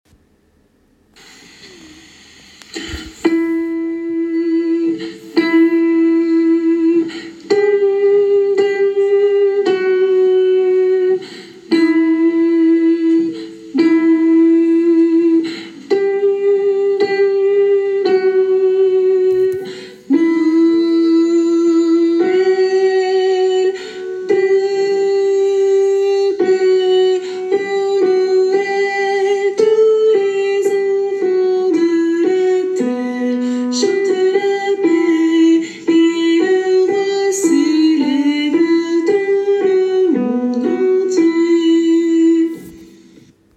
alto et autres voix en arrière plan